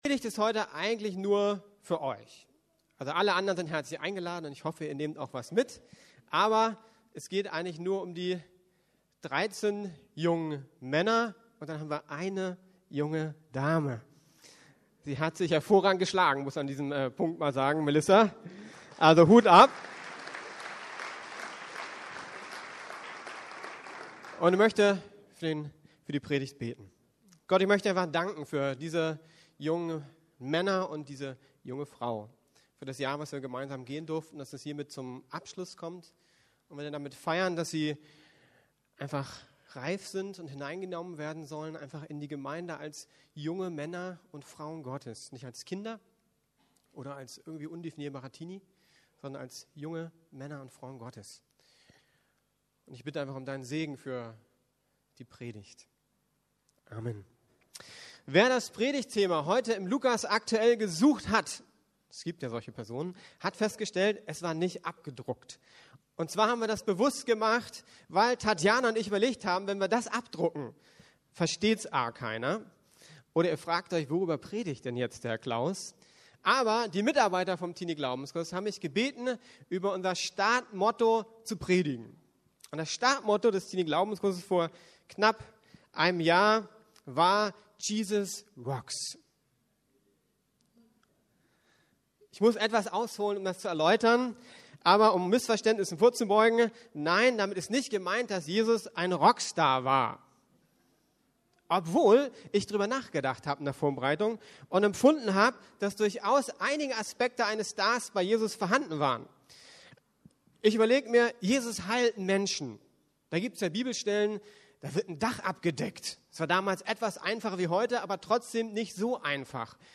Predigten der LUKAS GEMEINDE